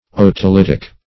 Search Result for " otolitic" : The Collaborative International Dictionary of English v.0.48: Otolithic \O`to*lith"ic\, Otolitic \O`to*lit"ic\, a. (Anat.) Of or pertaining to otoliths.